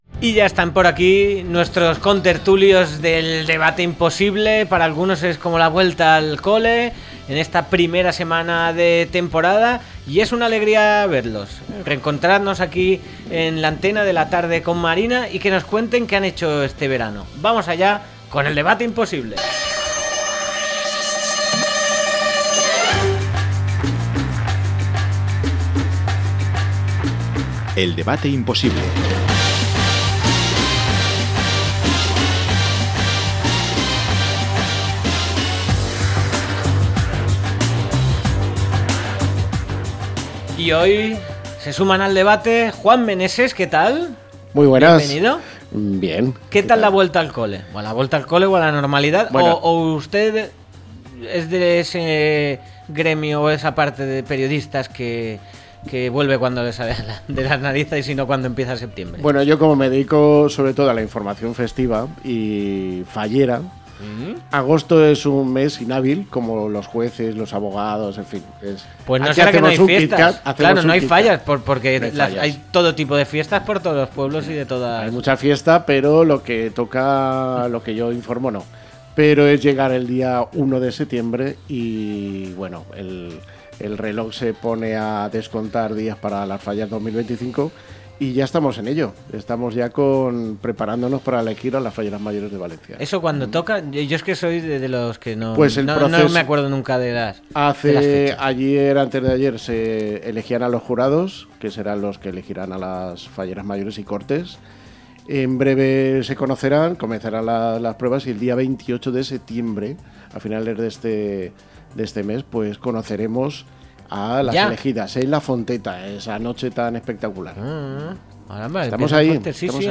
0905-LTCM-DEBATE.mp3